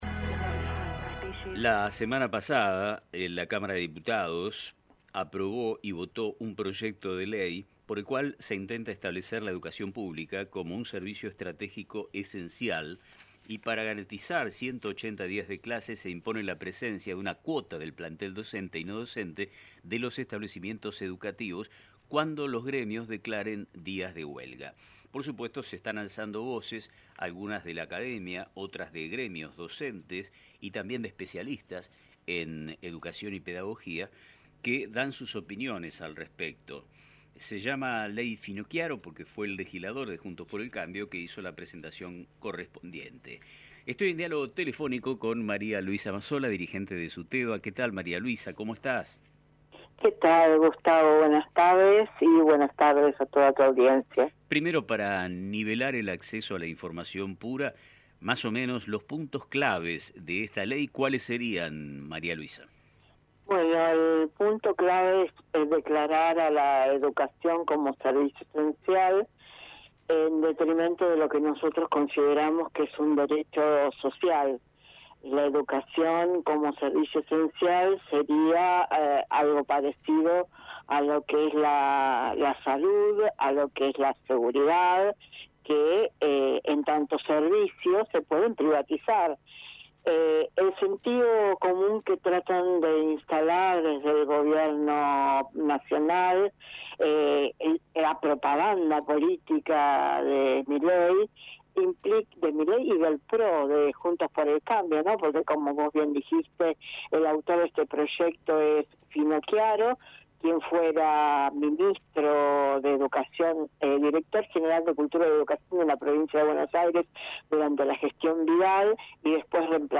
En una reciente entrevista